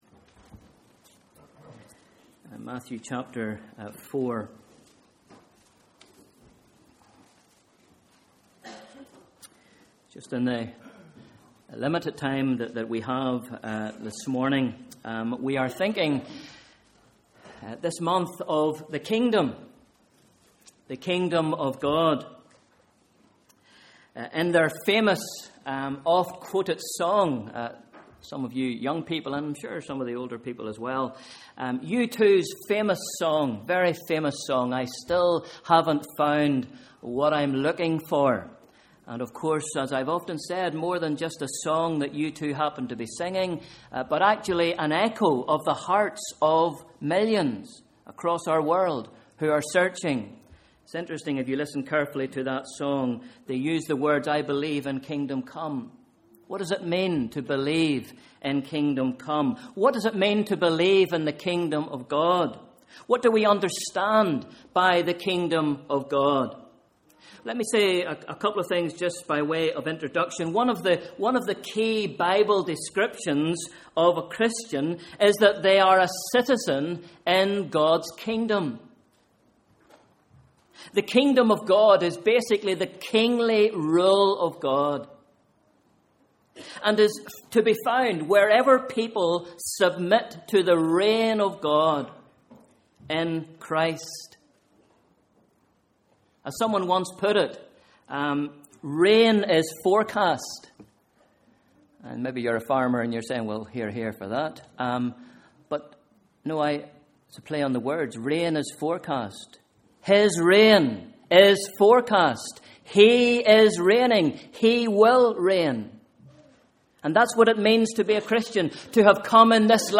Morning Service: Sunday 14th July 2013